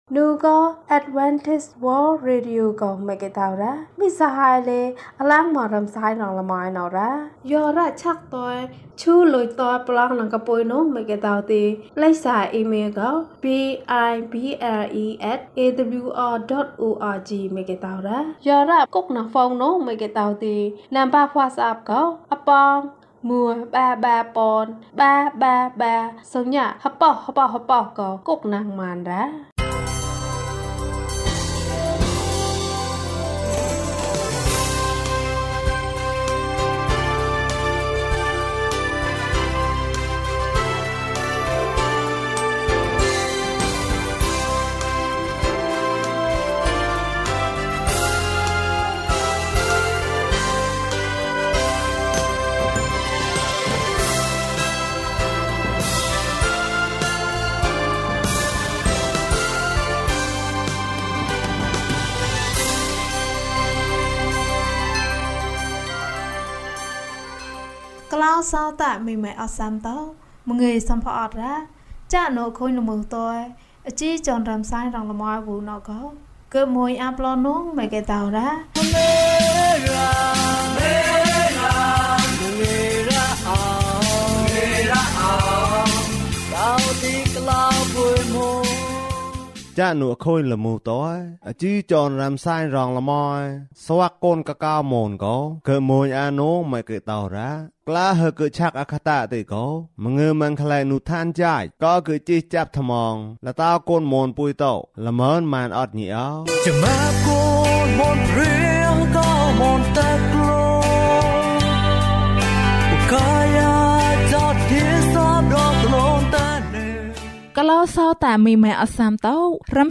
တိတ်ဆိတ်သောနေ့။ ကျန်းမာခြင်းအကြောင်းအရာ။ ဓမ္မသီချင်း။ တရားဒေသနာ။